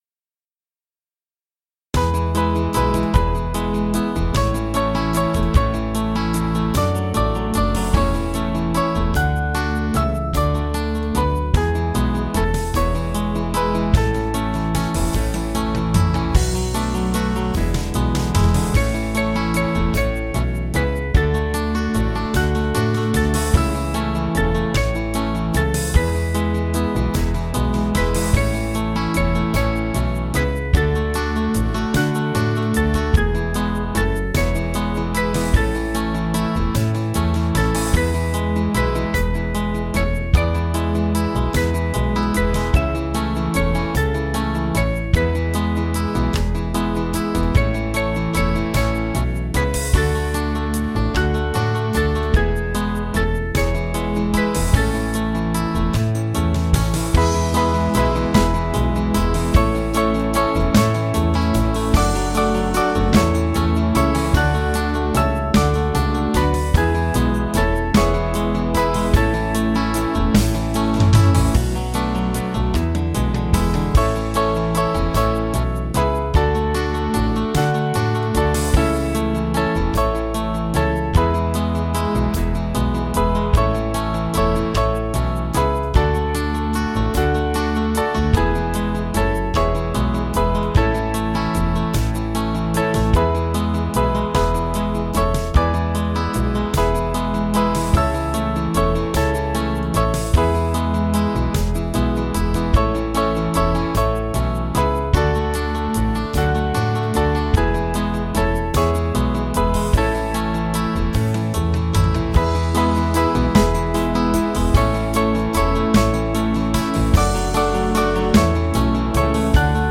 Organ
Small Band